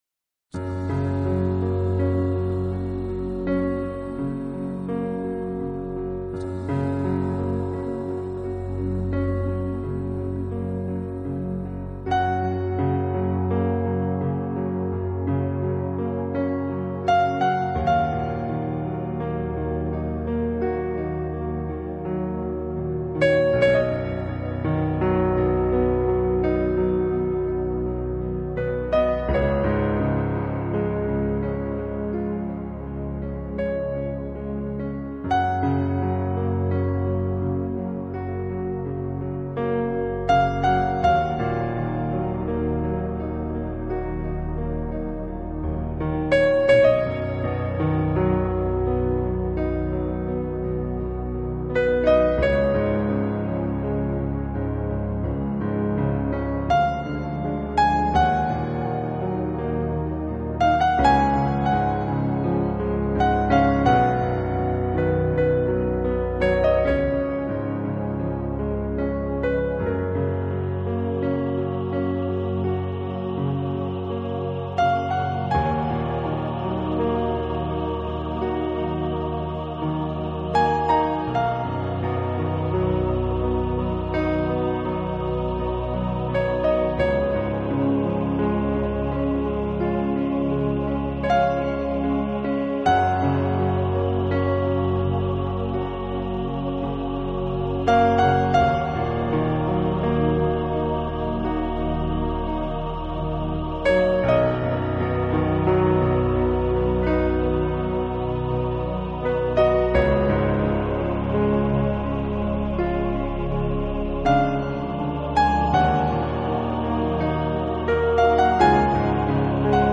他们的音乐更接近于“环境音乐”，以连绵不绝的电子合成音色营造空灵飘逸的
音乐氛围，旨在给人以完全放松安逸的音乐享受，其旋律几乎被弱化到了接近于
新纪元音乐让你放松